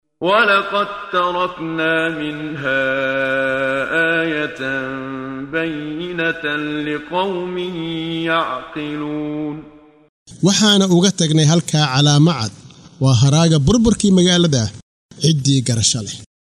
Waa Akhrin Codeed Af Soomaali ah ee Macaanida Suuradda Al-Cankabuut ( Caadhada ) oo u kala Qaybsan Aayado ahaan ayna la Socoto Akhrinta Qaariga Sheekh Muxammad Siddiiq Al-Manshaawi.